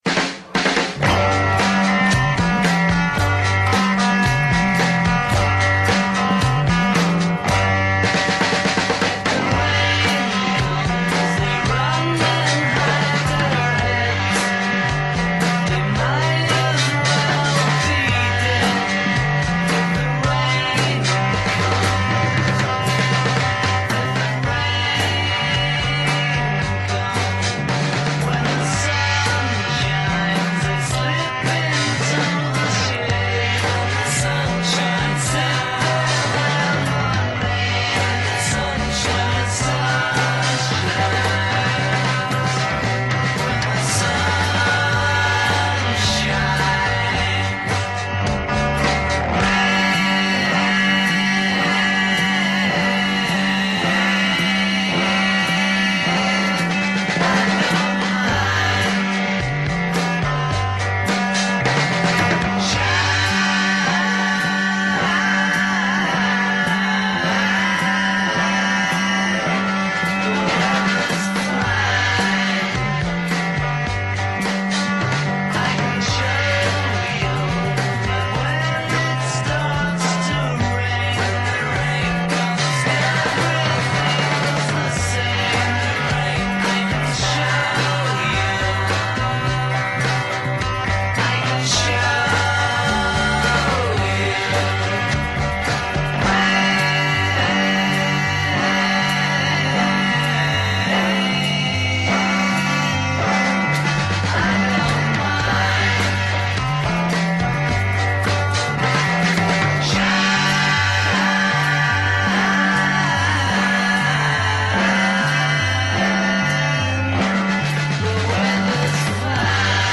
The sounds of storms, thunder, rain, and more.